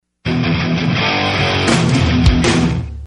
Главная » Рингтоны » SMS рингтоны
Категория: SMS рингтоны | Теги: SMS рингтоны, Hardcore